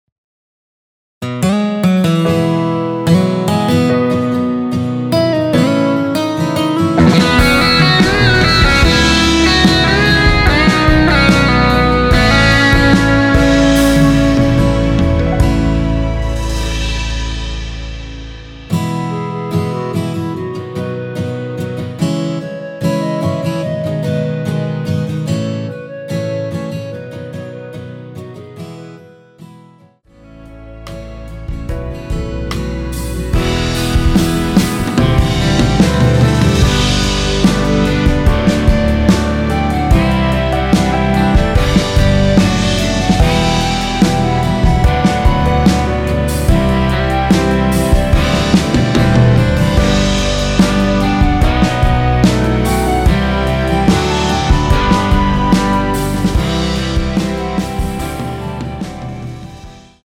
원키에서(-3)내린 멜로디 포함된 MR입니다.(미리듣기 확인)
멜로디 MR이라고 합니다.
앞부분30초, 뒷부분30초씩 편집해서 올려 드리고 있습니다.